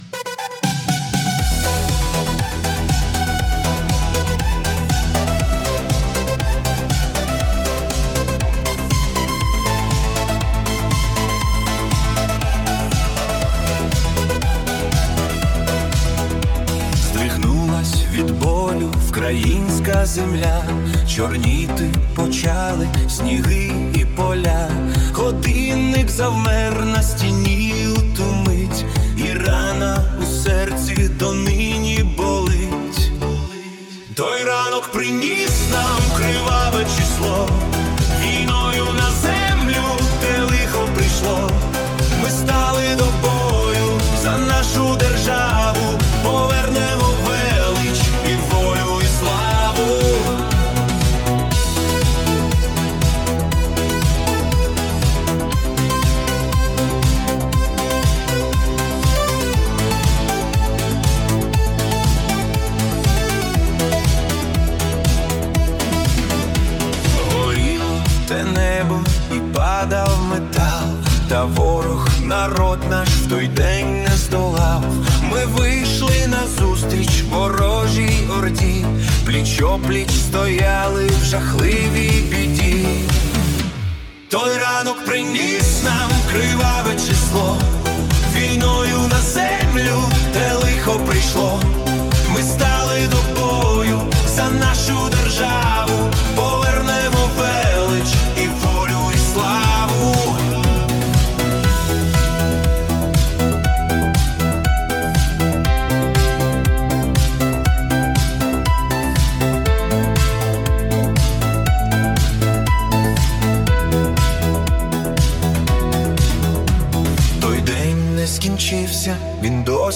Soulful Rock / Ballad